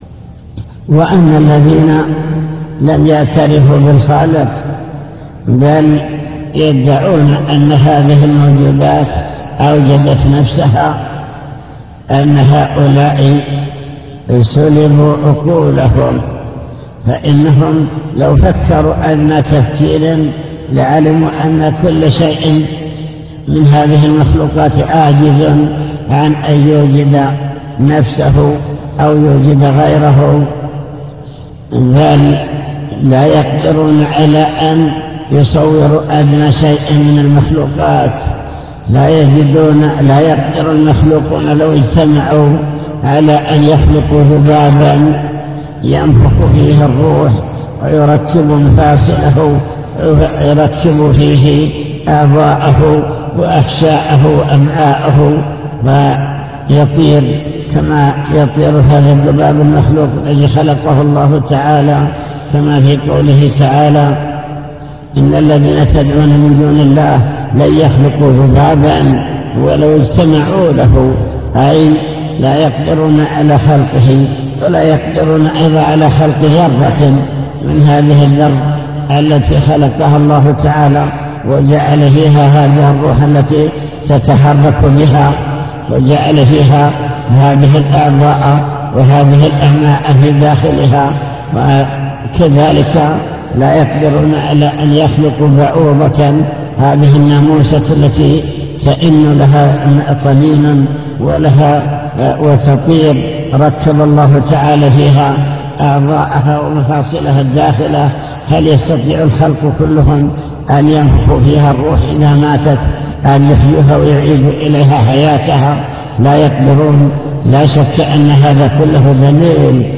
المكتبة الصوتية  تسجيلات - كتب  شرح كتاب بهجة قلوب الأبرار لابن السعدي شرح حديث يأتي الشيطان أحدكم فيقول من خلق كذا